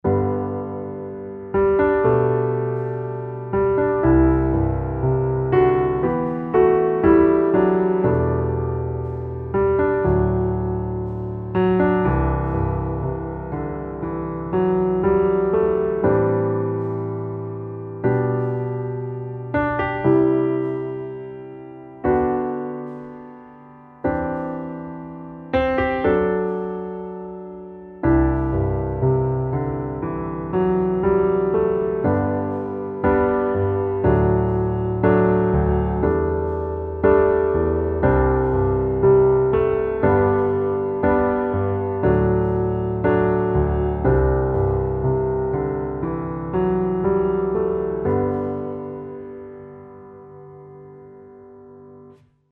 underscores for contemporary worship
piano demo